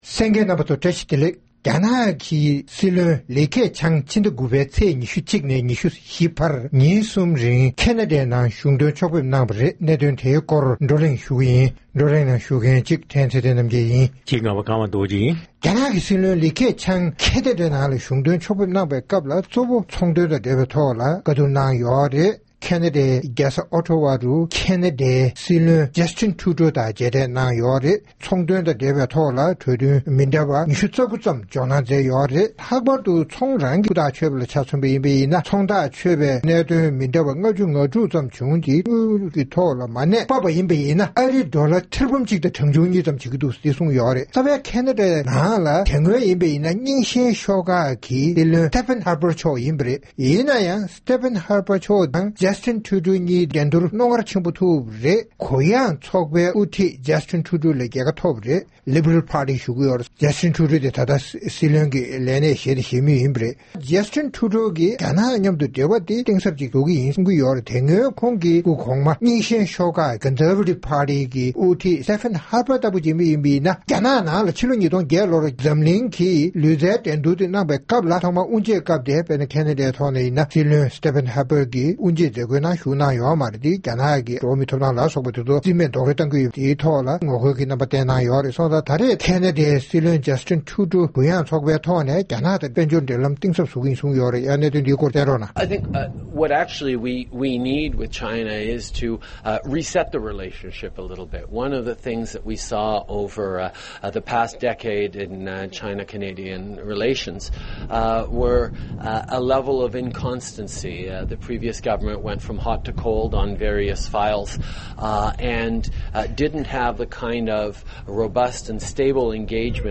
༄༅། །ཐེངས་འདིའི་རྩོམ་སྒྲིག་པའི་གླེང་སྟེགས་ཞེས་པའི་ལེ་ཚན་ནང་། རྒྱ་ནག་གི་སྲིད་བློན་ལི་ཁེ་ཆང་ཁེ་ཎ་ཌའི་ནང་ཉིན་གྲངས་གསུམ་རིང་གཞུང་དོན་ཕྱོགས་སྐྱོད་གནང་སྟེ་དཔལ་འབྱོར་གྱི་ཆིངས་དོན་མང་དག་ཅིག་བཞག་པ་སོགས་ཀྱི་སྐོར་རྩོམ་སྒྲིག་འགན་འཛིན་རྣམ་པས་བགྲོ་གླེང་གནང་བ་ཞིག་གསན་རོགས་གནང་།